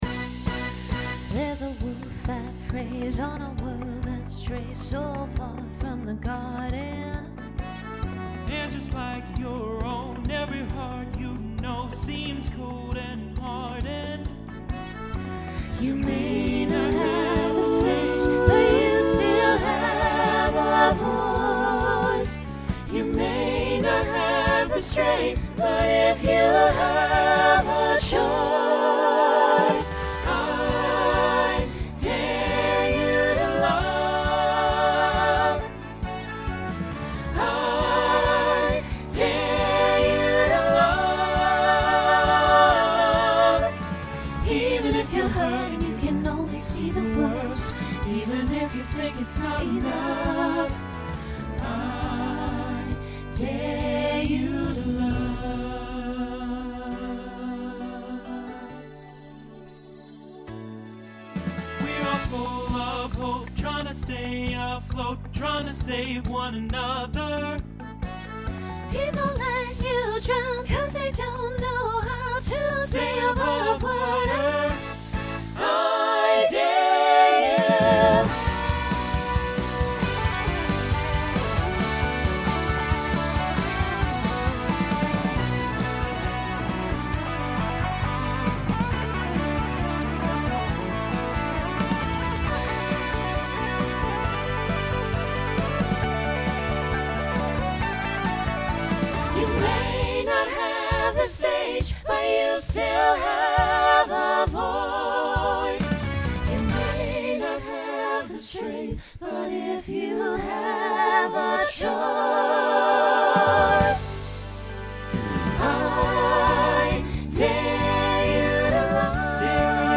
Pop/Dance
Voicing SATB